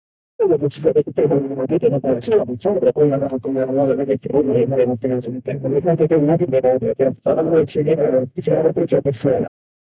Royalty-free spaceship sound effects
Overwhelming and heavy. 0:10 Pushing a button with phaser on scifi hi-tech space ship to open door 0:05 4 aliens qui parlent dans leur langue dans un vaisseau 0:10 MassIVE Sci-Fi Spaceship Flyby.
4-aliens-qui-parlent-dans-aievkfbh.wav